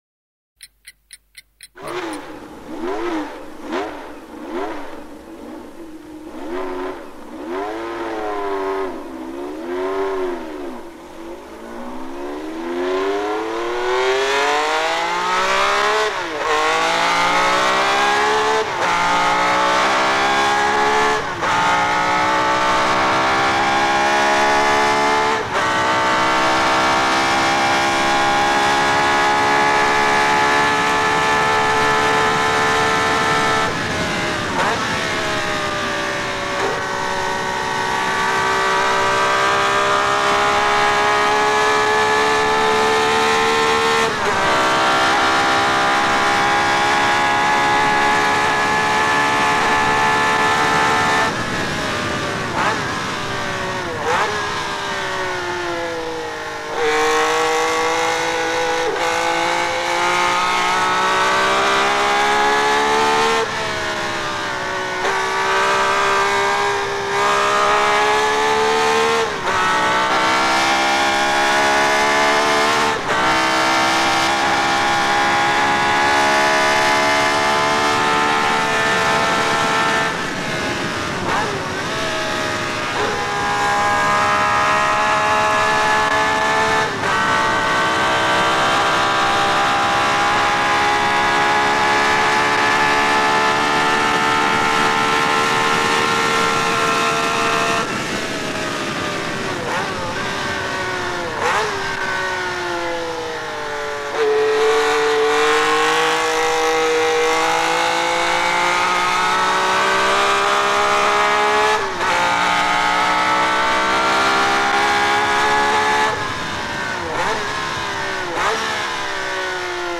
Ascolta il *vero* suono del
V12 Ferrari (mp3 - 2,1 MByte).
ferrari.mp3